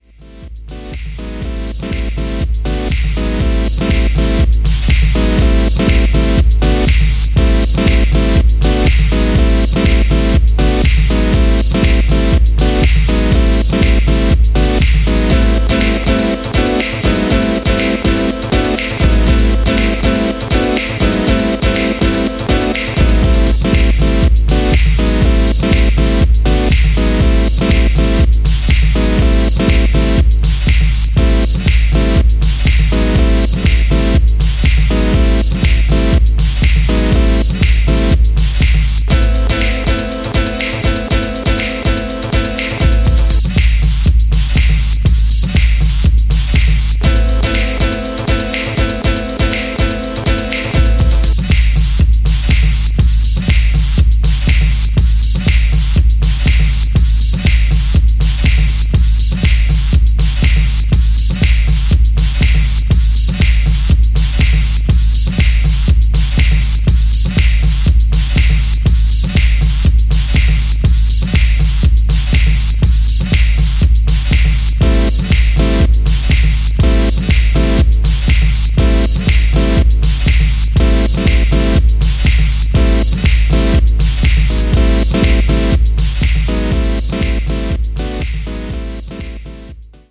ジャンル(スタイル) JAZZ / HOUSE / DOWNTEMPO / CLASSIC / DEEP HOUSE